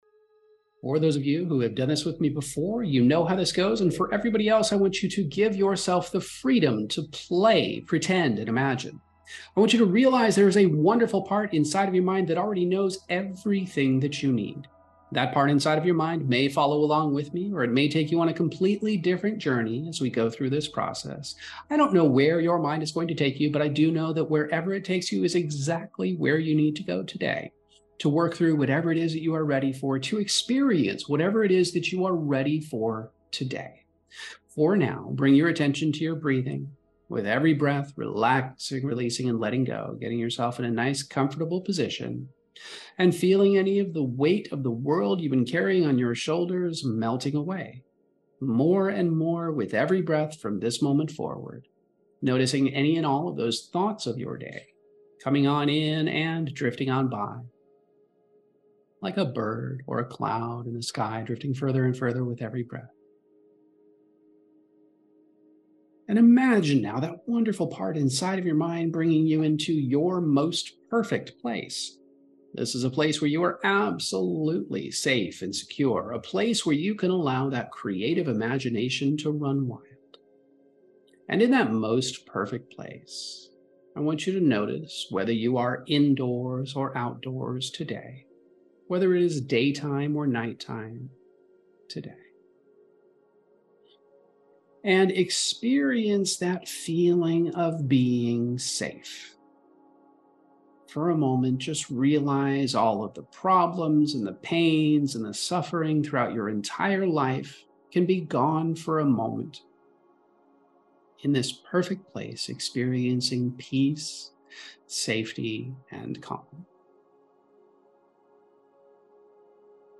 This guided hypnosis meditation is like a deep dive into your psyche, flipping the script from feeling beaten down by life to discovering your inner superhero. It's all about kicking back, letting go of the day's drama, and then journeying to a place in your mind where you're totally safe and in control.
Emotional Optimization™ Meditations